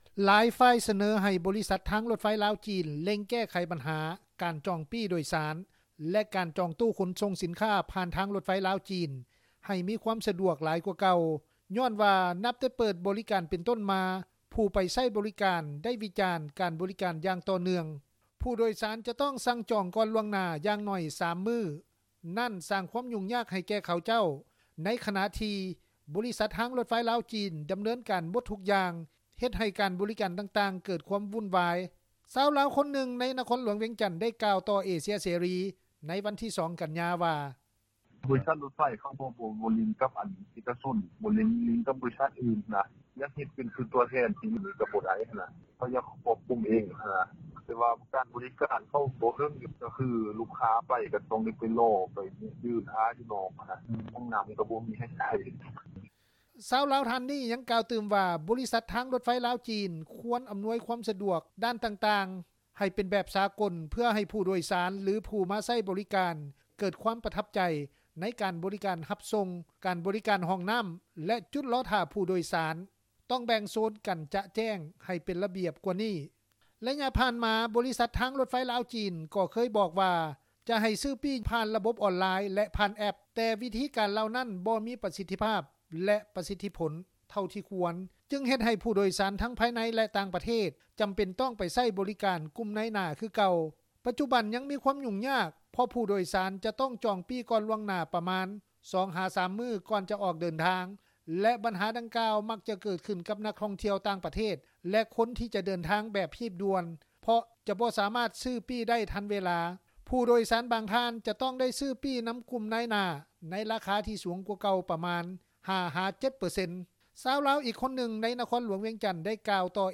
ຊາວລາວຄົນນຶ່ງ ໃນນະຄອນຫລວງວຽງຈັນ ໄດ້ກ່າວຕໍ່ວິທຍຸເອເຊັຽເສຣີ ໃນວັນທີ 2 ກັນຍາ ວ່າ:
ຊາວລາວອີກຄົນນຶ່ງ ໃນນະຄອນຫລວງວຽງຈັນ ໄດ້ກ່າວ ຕໍ່ວິທຍຸເອເຊັຽເສຣີ ວ່າ: